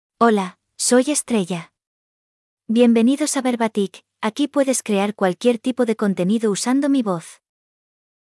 Estrella — Female Spanish (Spain) AI Voice | TTS, Voice Cloning & Video | Verbatik AI
FemaleSpanish (Spain)
Estrella is a female AI voice for Spanish (Spain).
Voice sample
Female
Estrella delivers clear pronunciation with authentic Spain Spanish intonation, making your content sound professionally produced.